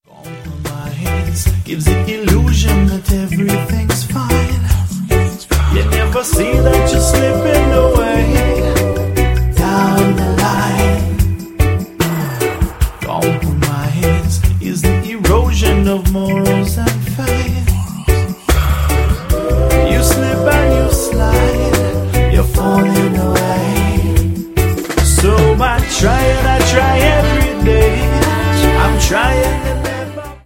• Sachgebiet: Reggae